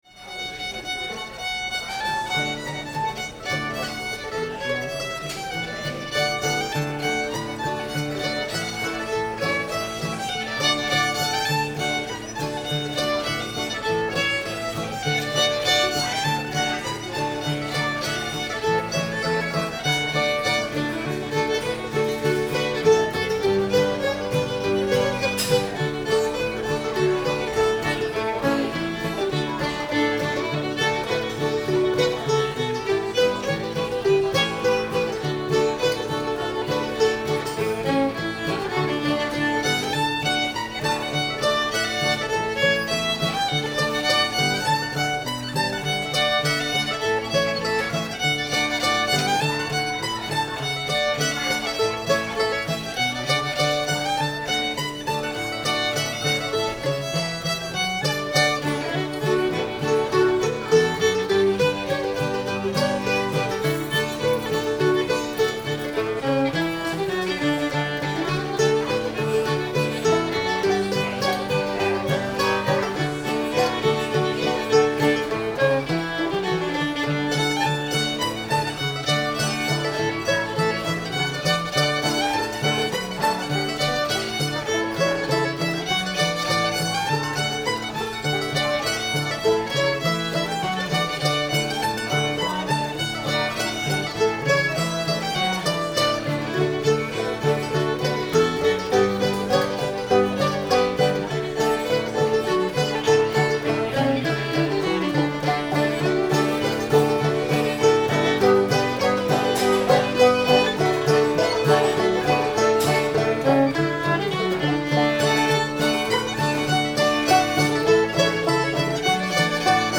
cheat mountain [D]